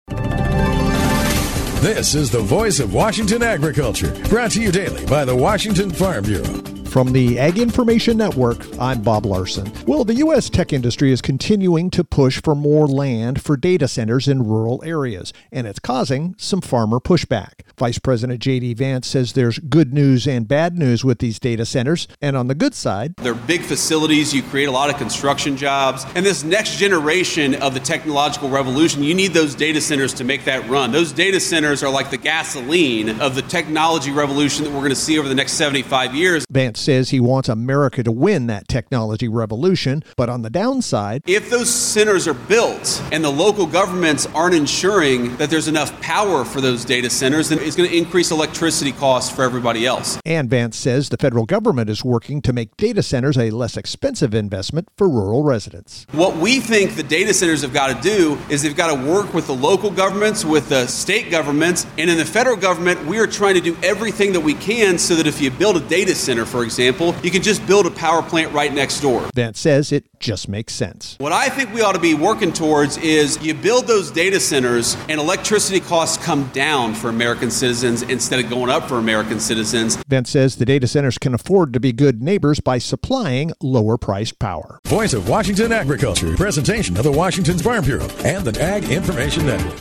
Wednesday Mar 11th, 2026 55 Views Washington State Farm Bureau Report